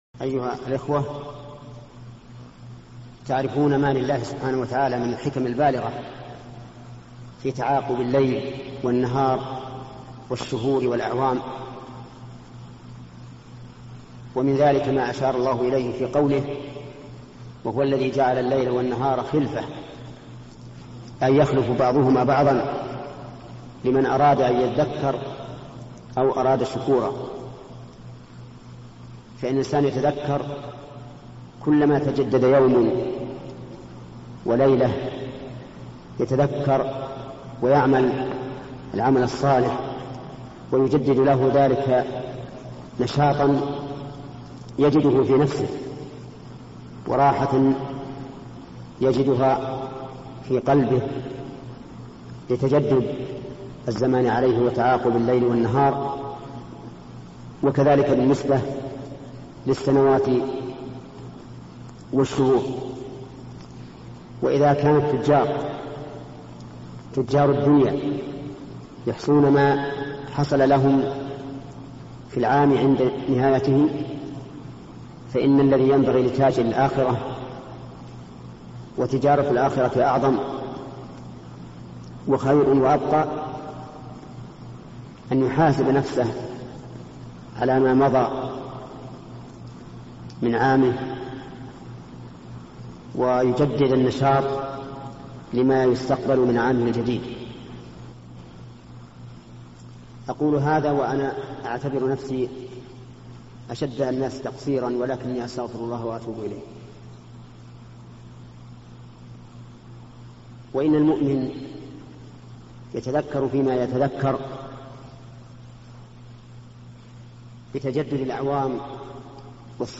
موعظة في استغلال أوقات الفراغ بالطاعات - الشيخ ابن عثيمين - مشروع كبار العلماء